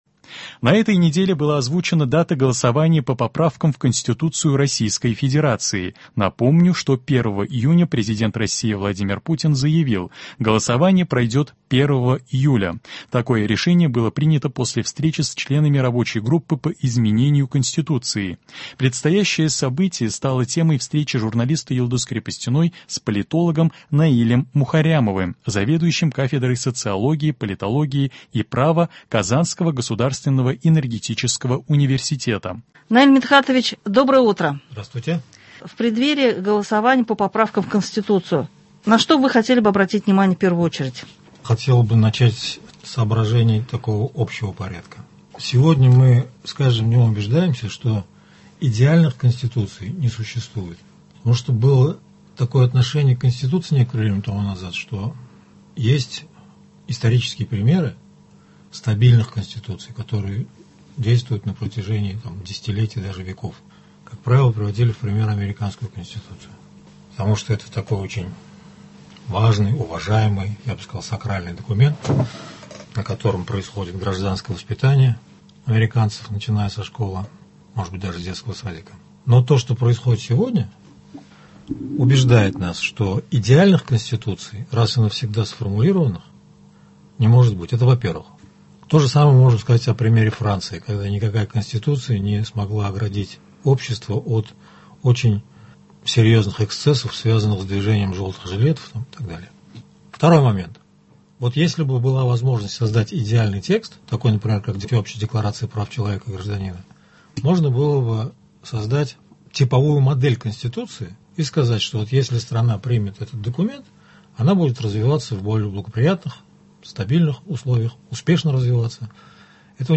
В преддверии 1 июля — Дня всенародного голосования по поправкам в Конституцию Российской Федерации звучит запись беседы с политологом